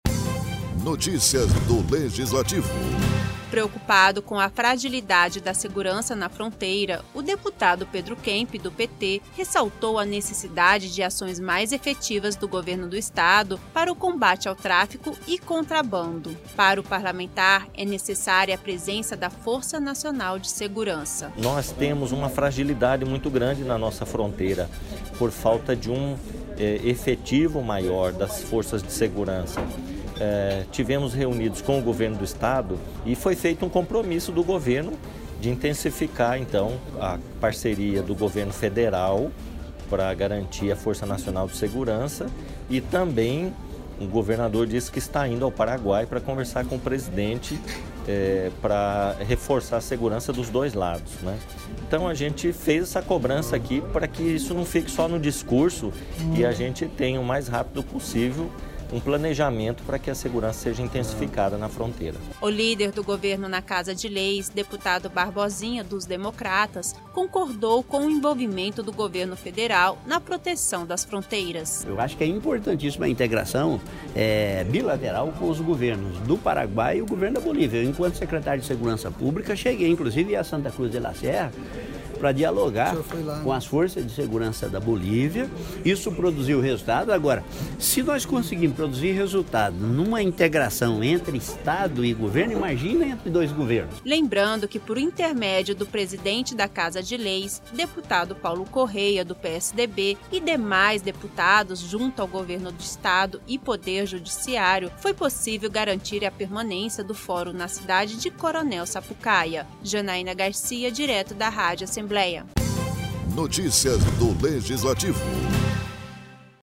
Na sessão plenária desta quinta-feira (4) na Assembleia Legislativa, o deputado Pedro Kemp (PT) repercutiu na tribuna a necessidade de manter em funcionamento o Fórum de Coronel Sapucaia e a necessidade de reforço policial para combate ao tráfico e contrabando.